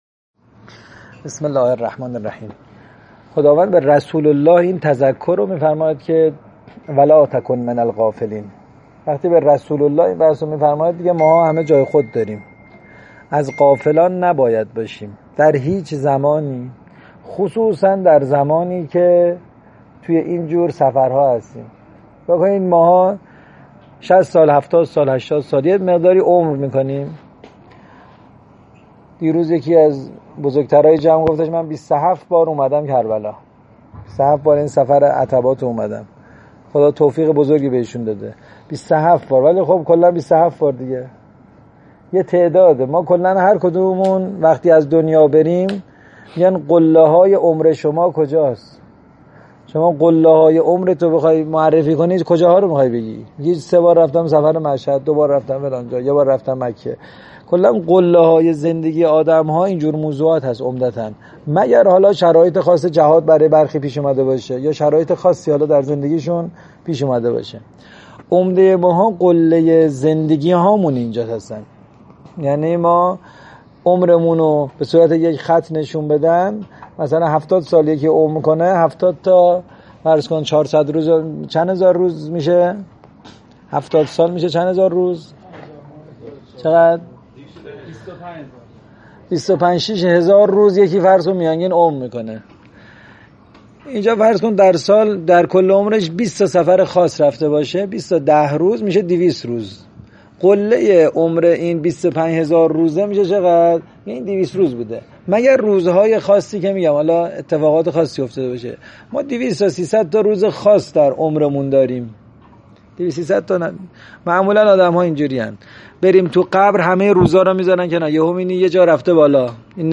دوره های معارفی تمسک اربعین 1402
فایل صوتی سخنرانی